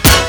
SHORT STAB.wav